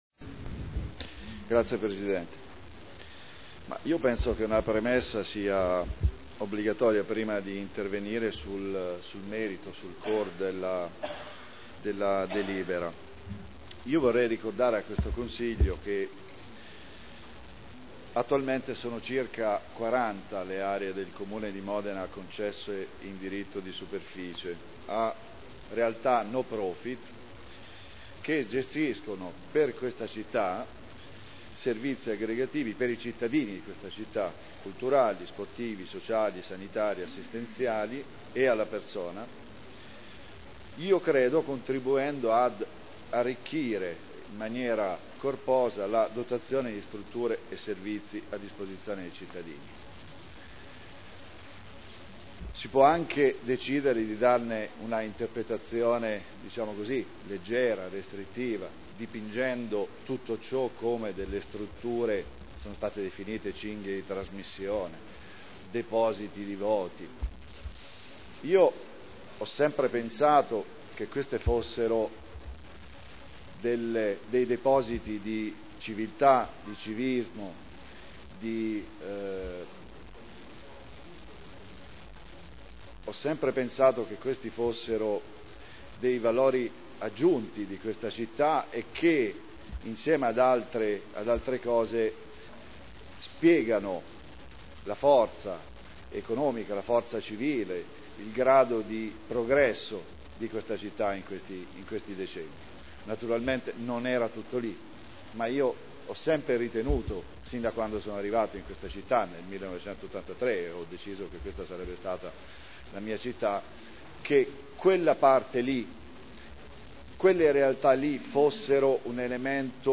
Paolo Trande — Sito Audio Consiglio Comunale